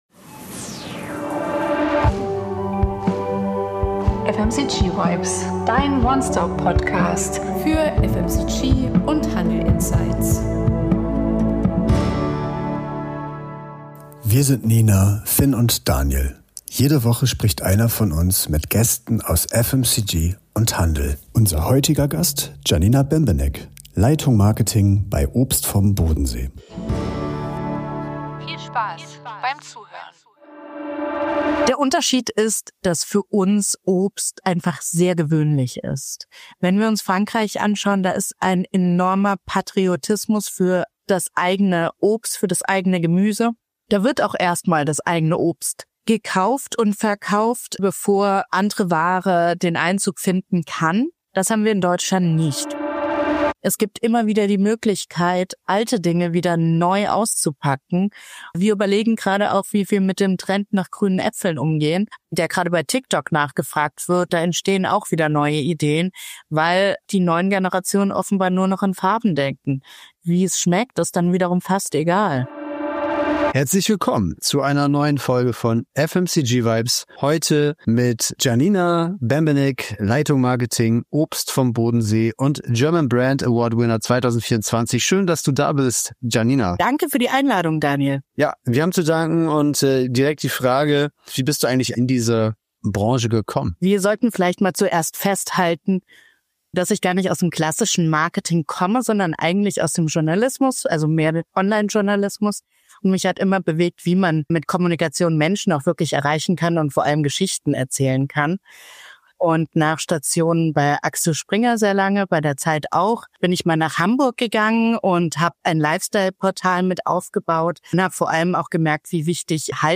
Expertinnentalk